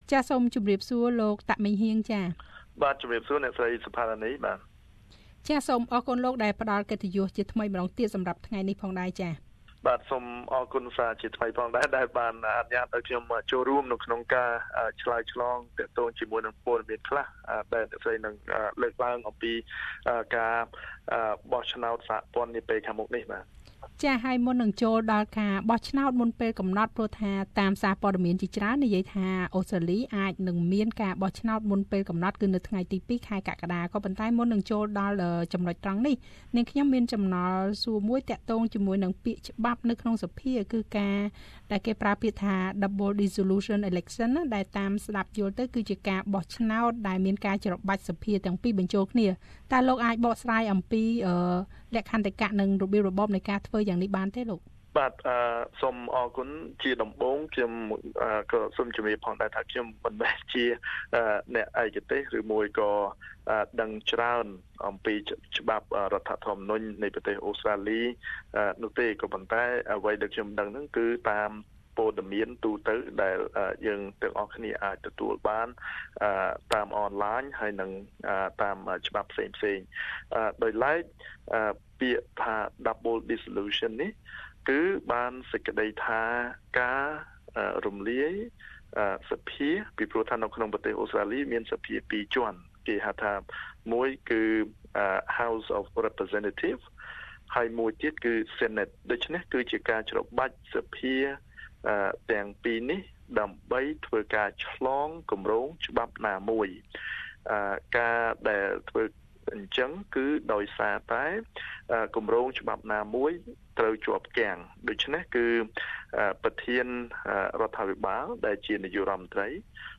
បទសម្ភាសន៍ជាមួយនឹងលោកចៅហ្វាយក្រុងនៃ City of Greater Dandenong លោក តាកម៉េងហ៊ាង ស្តីពីការបោះឆ្នោតដែលត្រូវរំលាយឬច្របាច់សភាទាំងពីរថ្នាក់បញ្ចូលគ្នា។ ហេតុអ្វីបានជាគេត្រូវធ្វើបែបនេះ?តើវាមានពាក់ព័ន្ធអ្វីដល់ការបោះឆ្នោតមុនពេលកំណត់ដែរឬទេ?